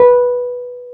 PNO_T.Wurly B_5b.wav